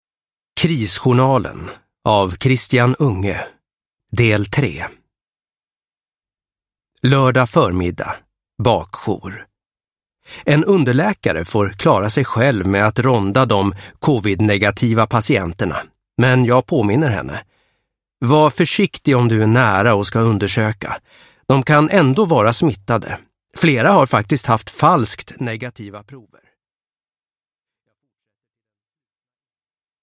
Krisjournalen - 3 - "Se till att masken sitter tätt" – Ljudbok – Laddas ner